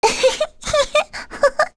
Rehartna-Vox_Happy2.wav